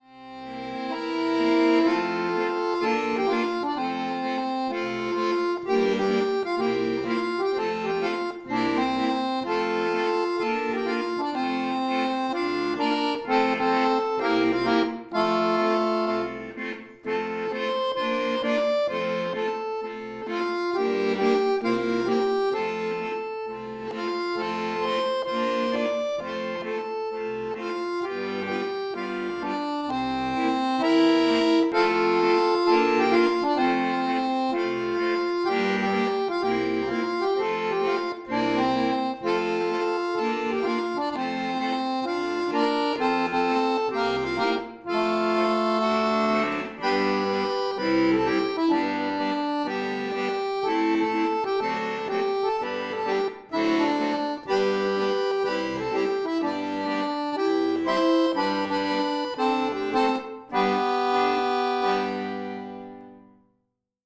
Einfach bis mittel
Gospels & Spirituals, Weihnachtslied